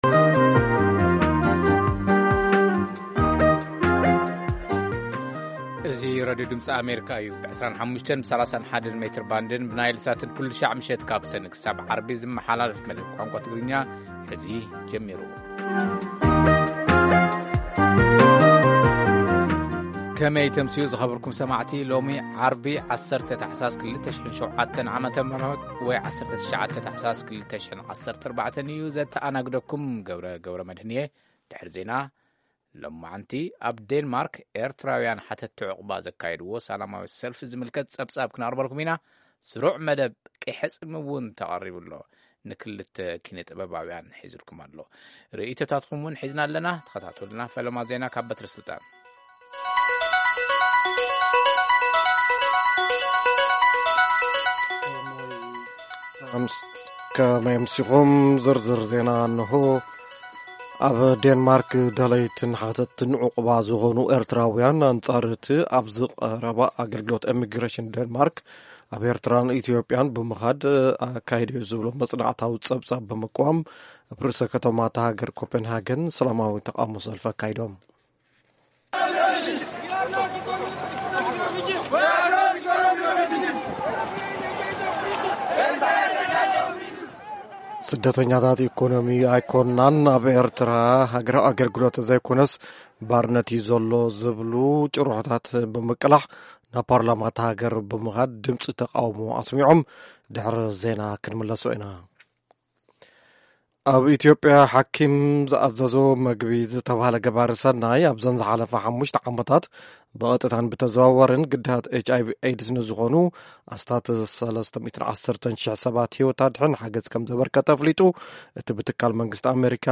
ፈነወ ትግርኛ ብናይ`ቲ መዓልቲ ዓበይቲ ዜና ይጅምር